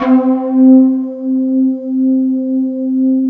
20 S.FLUTE-R.wav